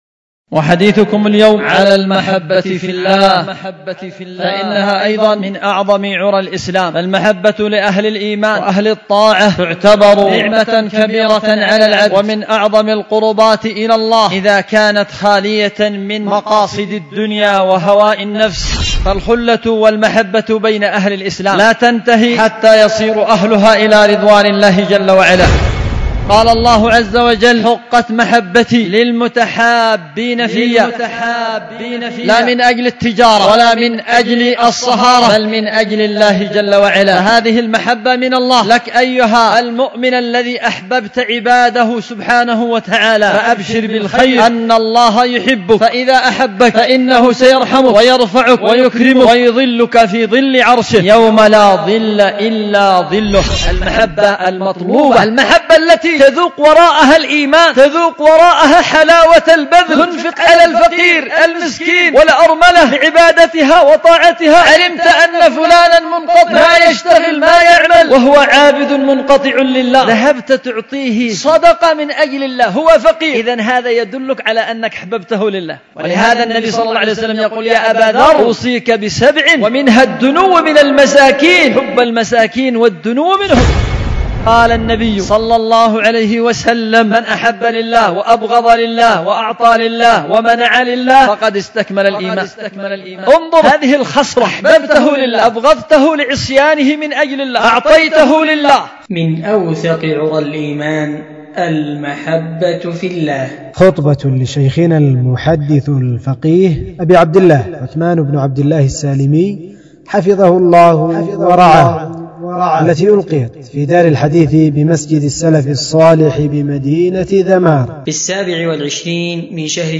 خطبة
في دار الحديث بمسجد السلف الصالح بذمار